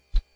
whoosh4.wav